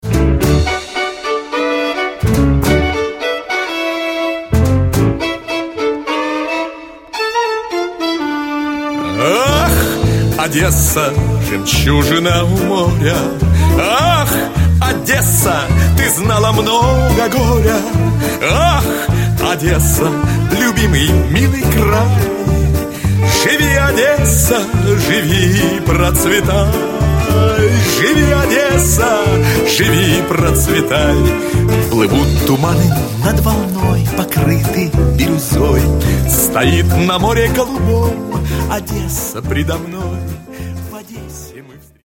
SWING Z KREMLA
Trochę tu brzmień bałkańskich, trochę coverów, np.
W niektórych utworach słychać echa muzyki klezmerskiej